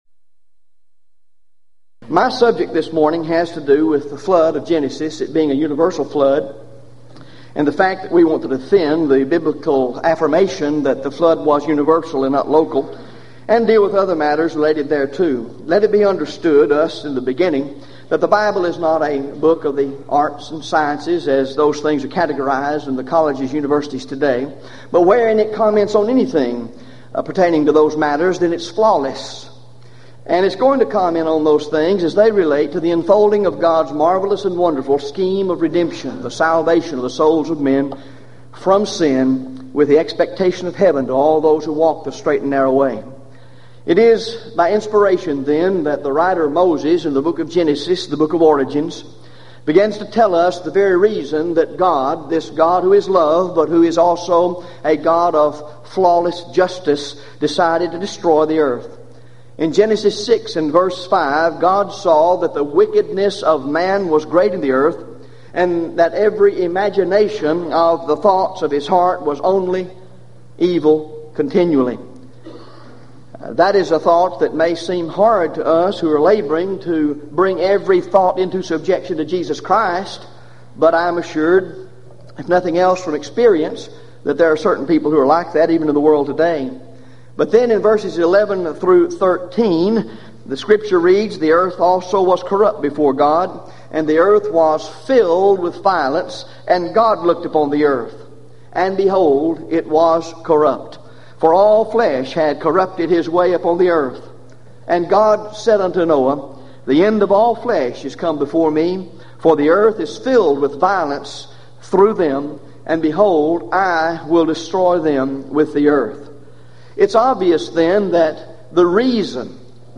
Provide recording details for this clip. Event: 1995 Gulf Coast Lectures